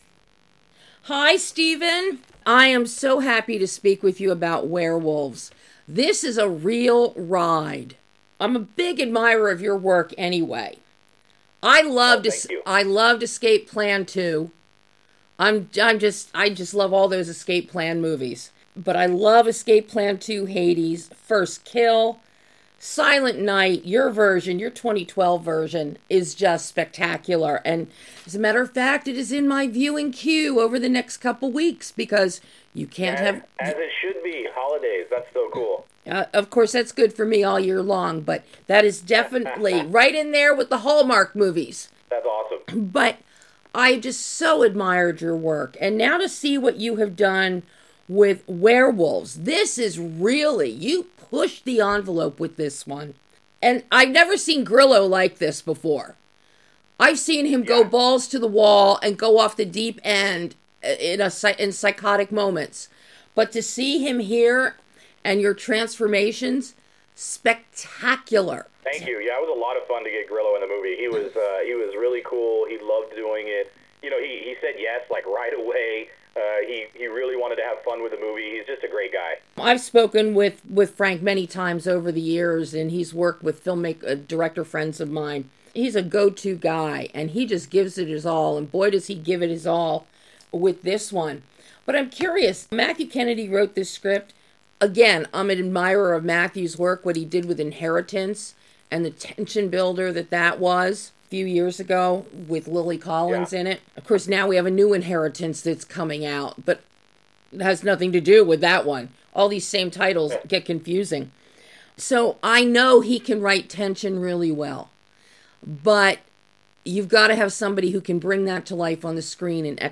WEREWOLVES - Exclusive Interview